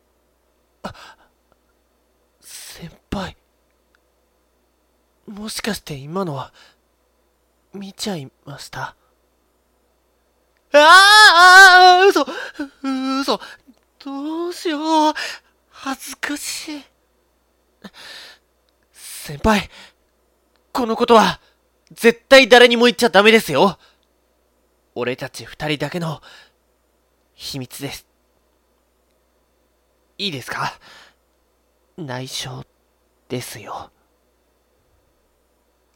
熱血な台詞にあこがれ、熱血なヤラレゼリフを吐きまくる(どうしてこうなった)人生ずれまくったヤローでございますw 真面目系、発狂系、ヘタレ系(笑)などを得意としておりますので、御用の際にはぜひぜひひとことくださいです～！